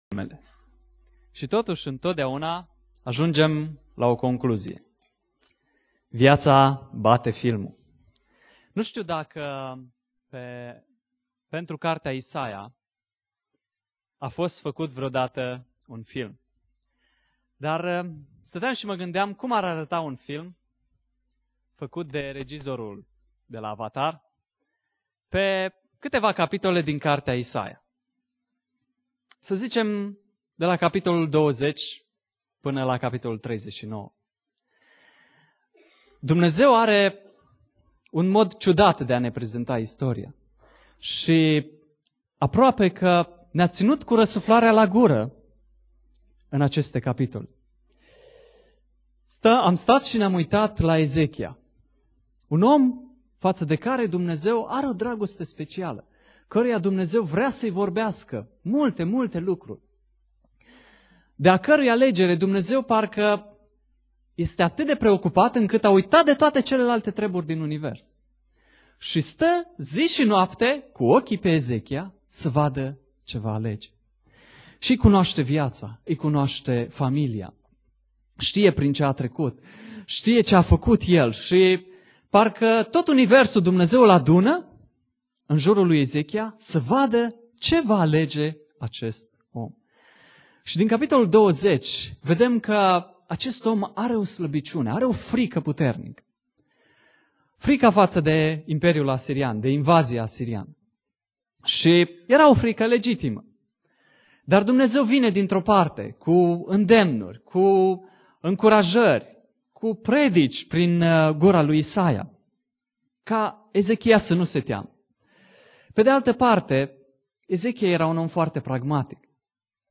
Predica Exegeza - Isaia 32-35